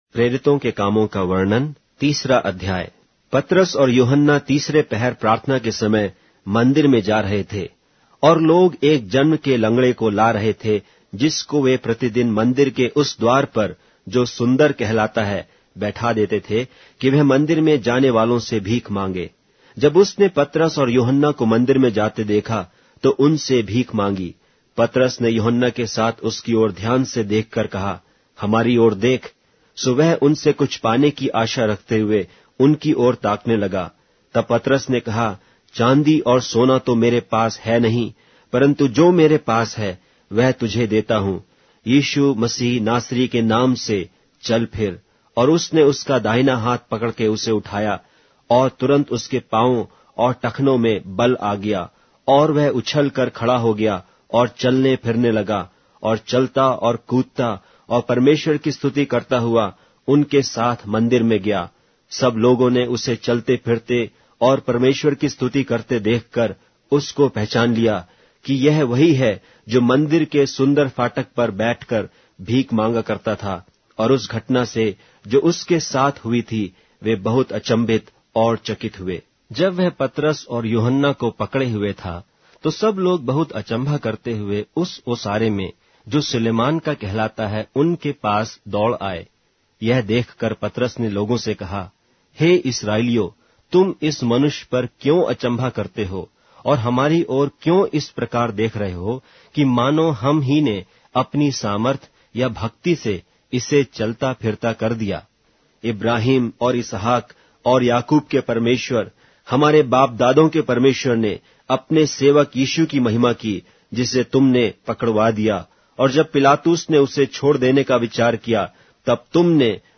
Hindi Audio Bible - Acts 23 in Irvgu bible version